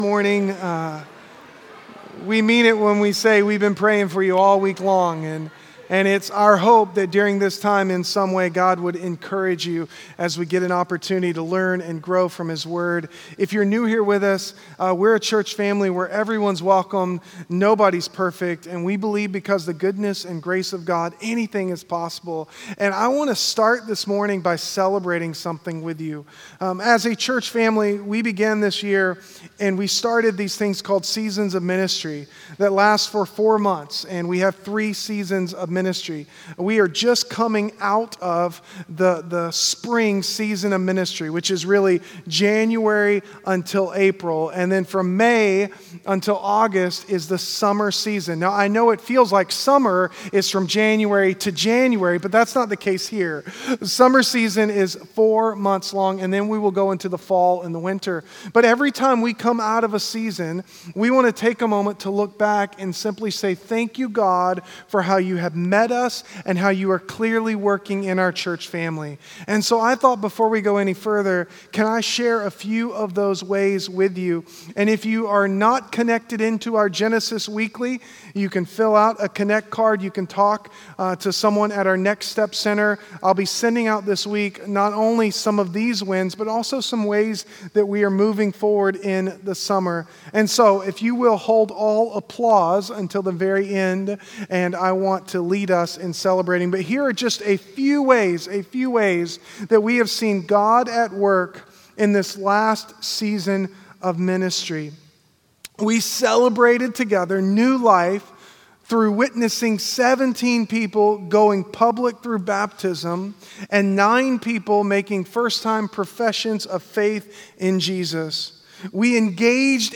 This is a sermon series to help you understand make sense of this unique Old Testament book.